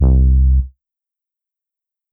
Bass (Water).wav